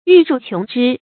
玉樹瓊枝 注音： ㄧㄩˋ ㄕㄨˋ ㄑㄩㄥˊ ㄓㄧ 讀音讀法： 意思解釋： ①形容樹木華美。②喻貴家子弟。